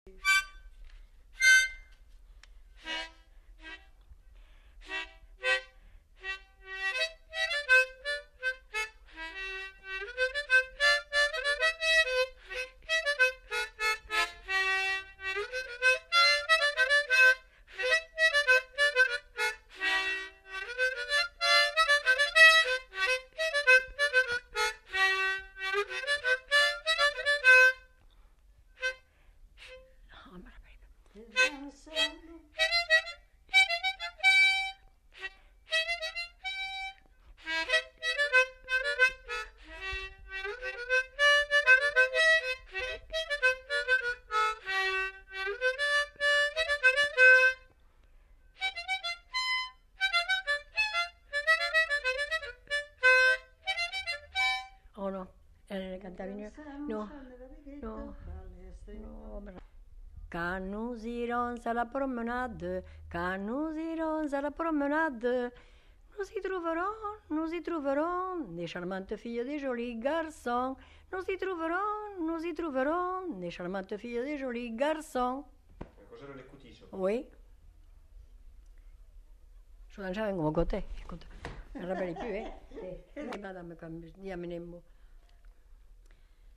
Aire culturelle : Haut-Agenais
Lieu : Cancon
Genre : morceau instrumental
Instrument de musique : harmonica
Danse : scottish
Notes consultables : L'interprète chante le thème.